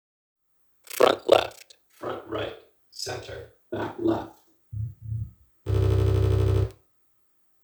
It's hard to hear the problem, so I moved the mic near the speaker and recorded again (notice the front left voice "quality"):